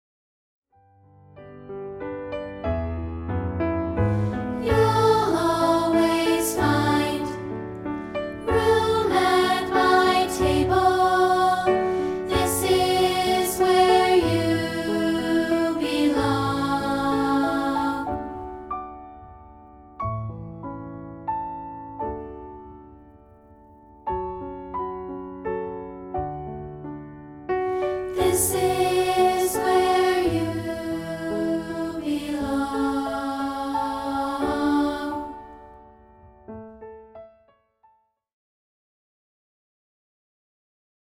No. 2 and made it available as a free rehearsal track.